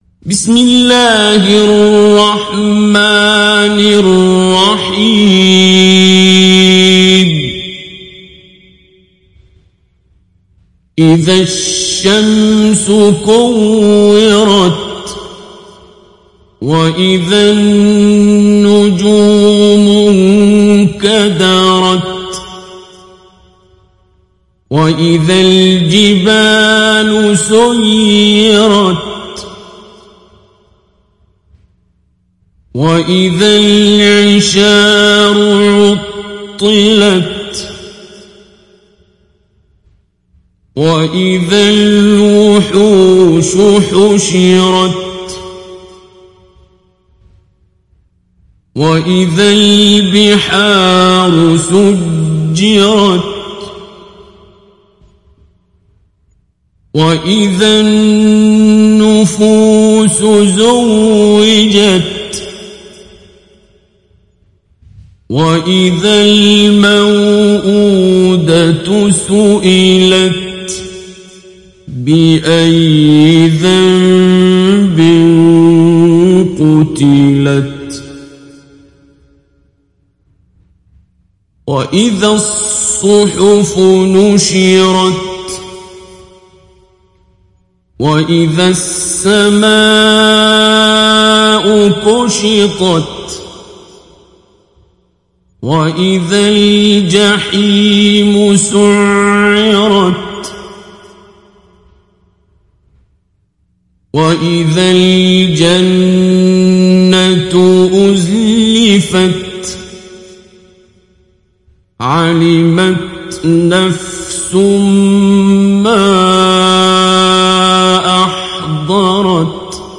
تحميل سورة التكوير mp3 بصوت عبد الباسط عبد الصمد مجود برواية حفص عن عاصم, تحميل استماع القرآن الكريم على الجوال mp3 كاملا بروابط مباشرة وسريعة
تحميل سورة التكوير عبد الباسط عبد الصمد مجود